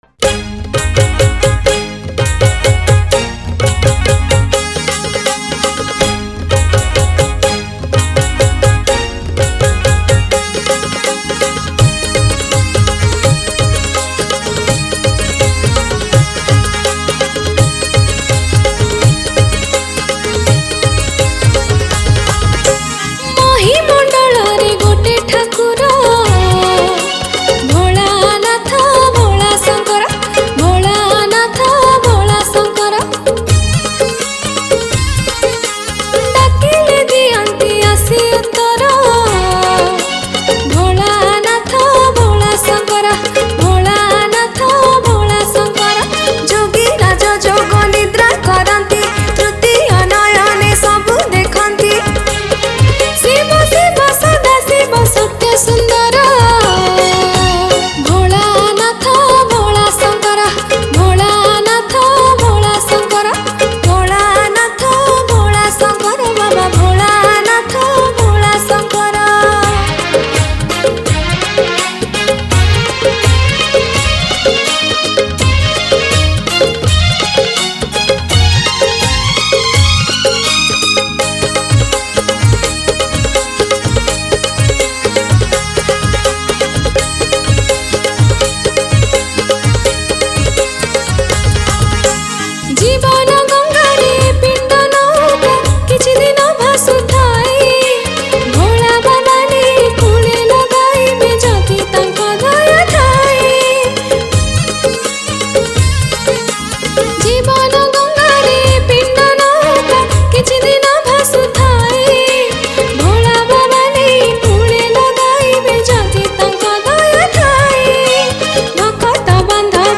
Odia Bhajan Song 2022 Songs Download